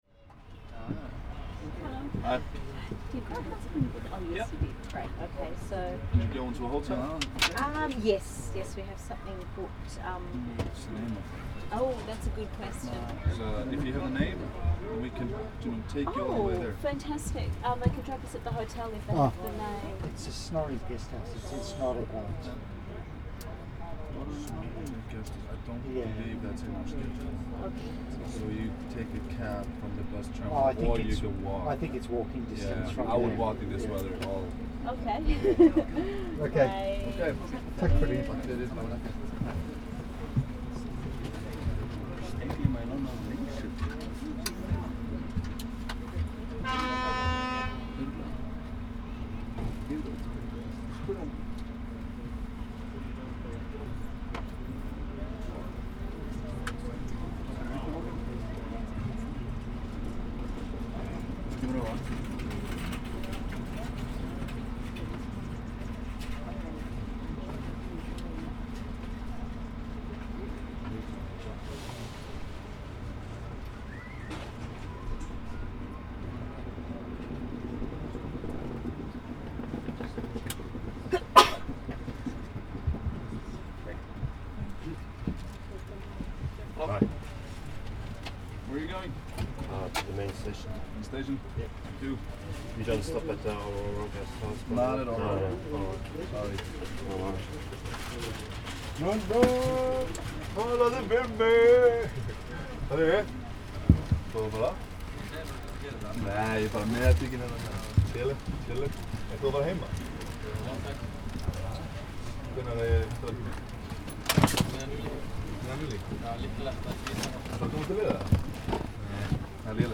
• place: Reykjavík, Iceland
Out there, outside the door is all this noise.
(00:05:55, stereo audio, 11.4 mb)